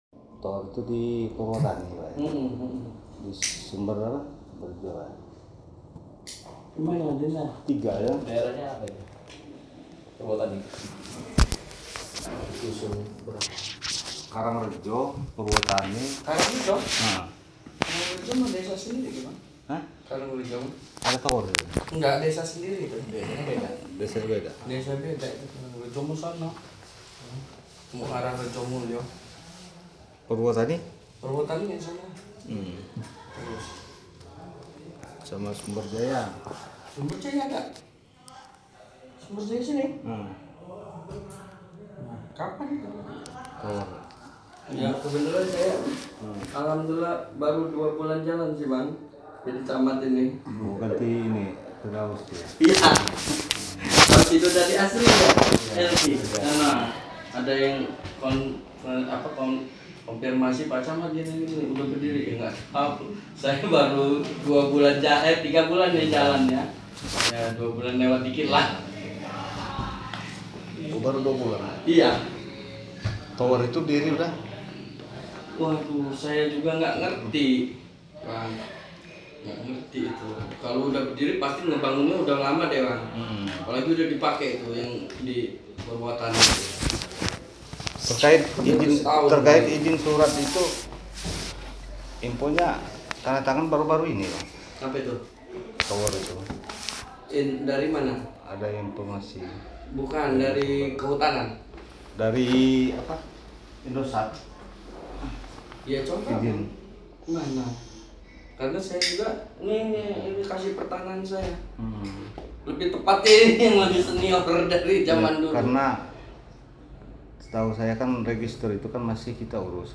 Keterangan dari Camat Jati Agung.